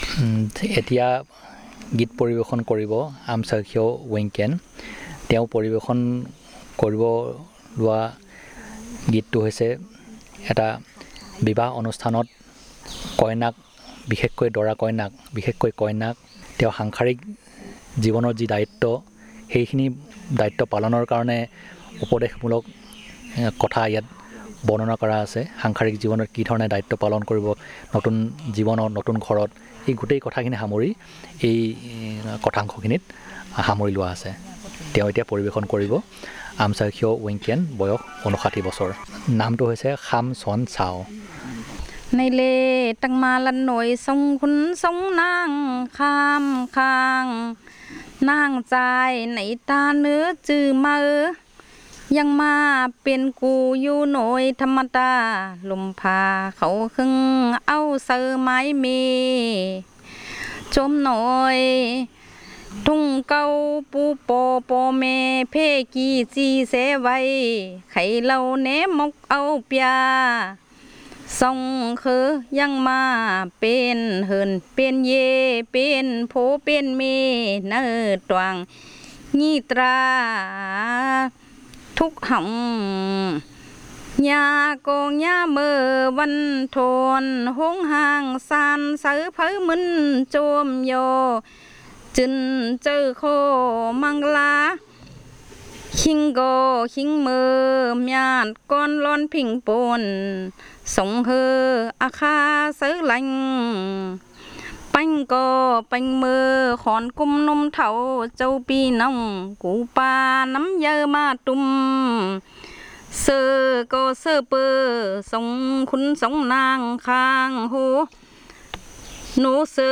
Performance of a song about marriages